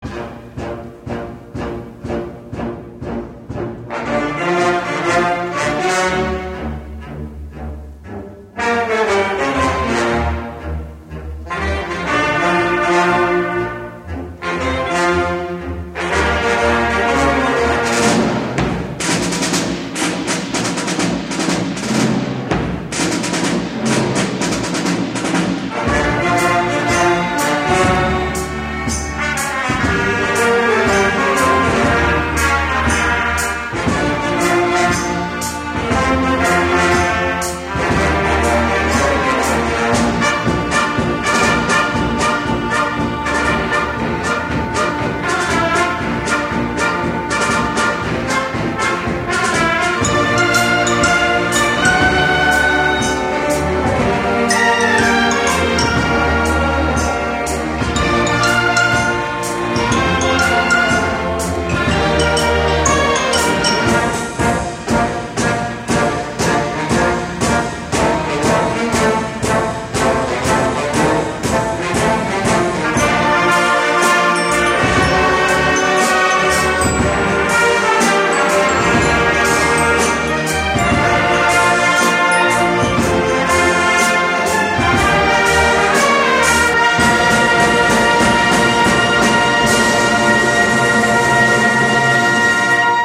VERSIONS INSTRUMENTALES